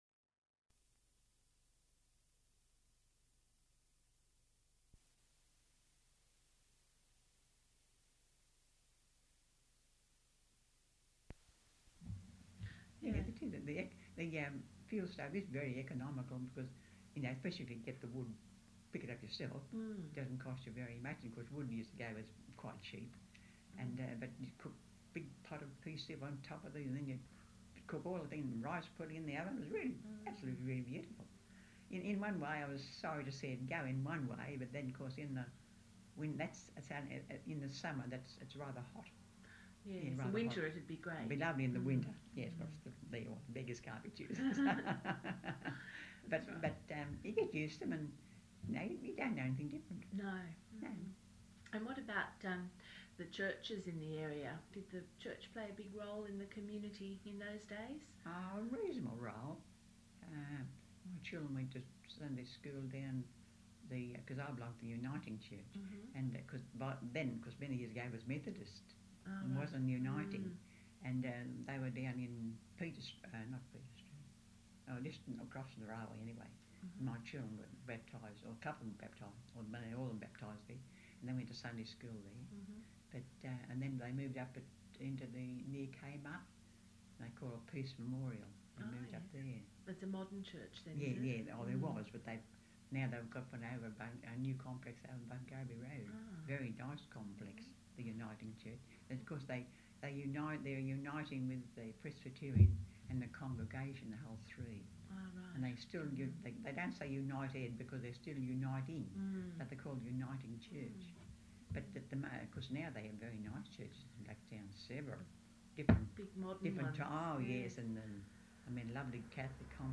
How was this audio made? audio cassette tape (1) Ingest Notes Side A some clipping (microphone bumped). Side B ingested at lower gain.